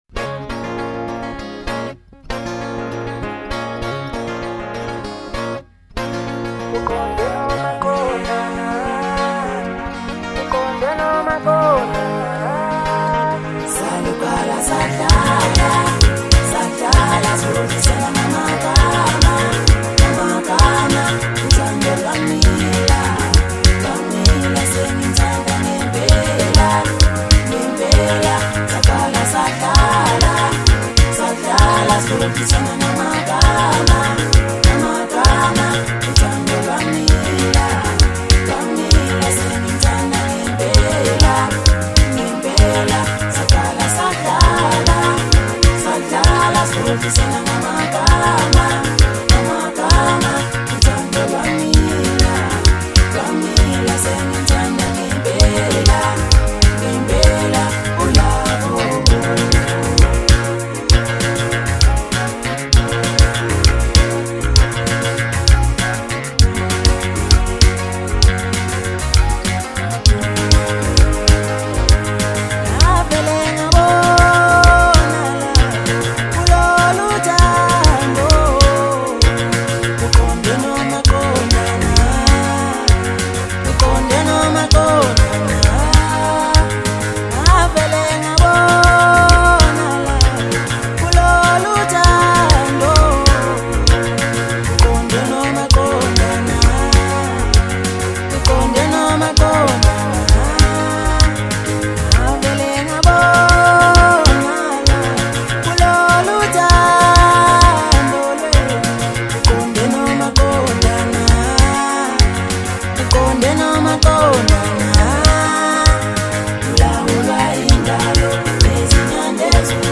Home » Maskandi
South African singer-songwriter